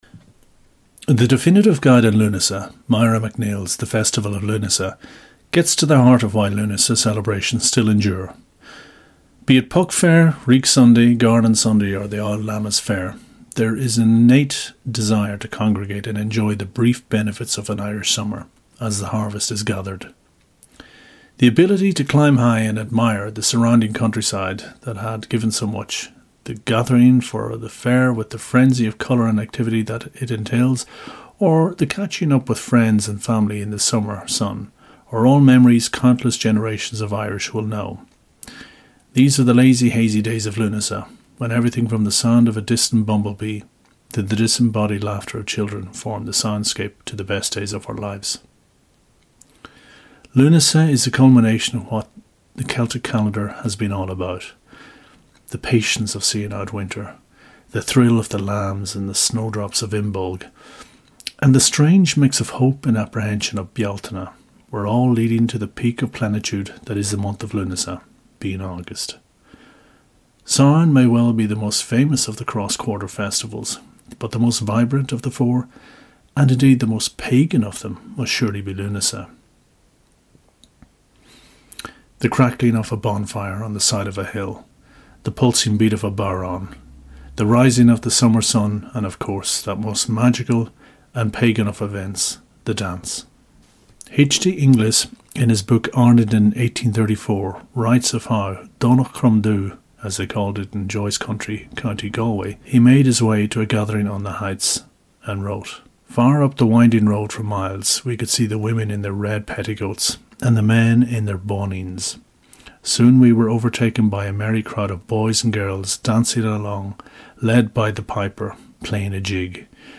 Sounds: Drummers